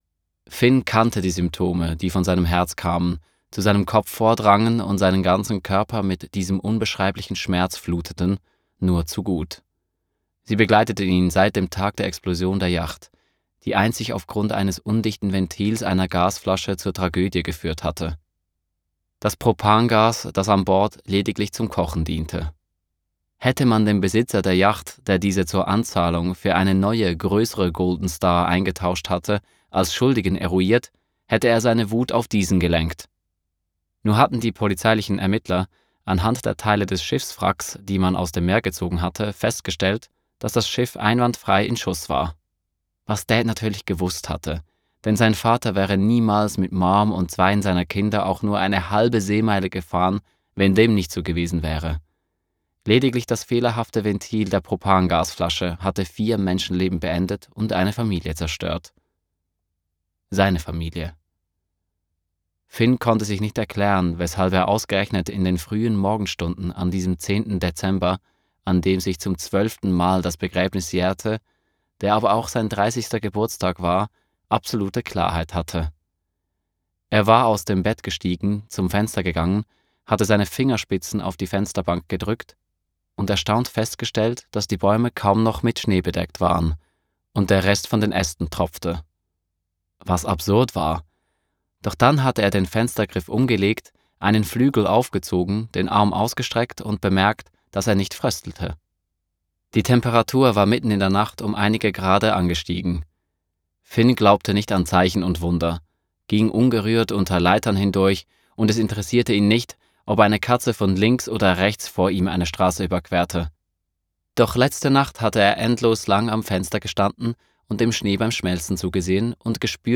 Hörbuch „Wenn Schnee zu Lebe schmilzt“
Hoerprobe_wennschneezuliebeschmilzt_Mann.wav